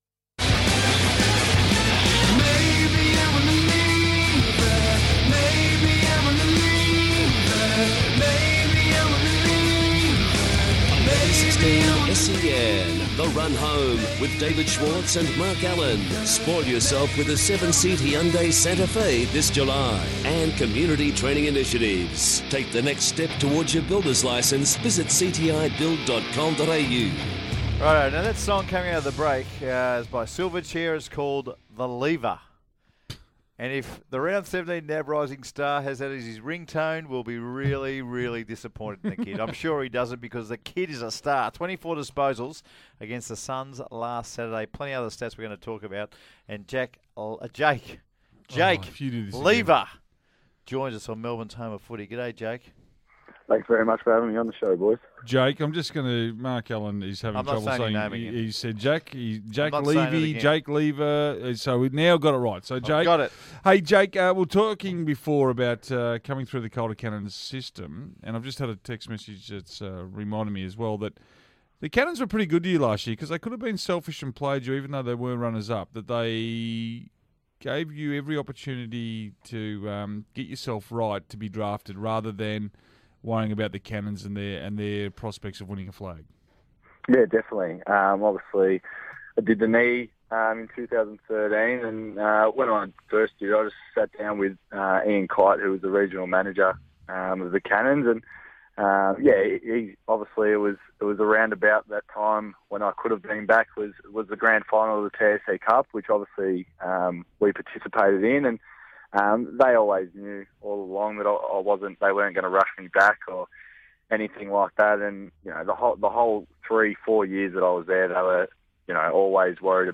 Round 17 Rising Star nominee Jake Lever spoke on Melbourne radio station SEN